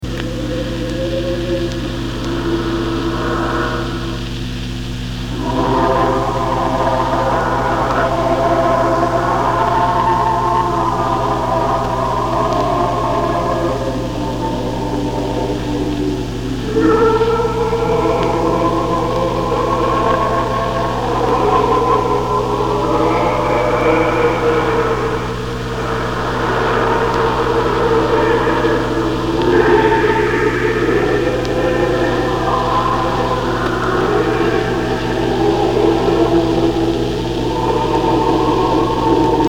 old vacuum tube radio
sometimes sing to him in a sort of other-wordly chorus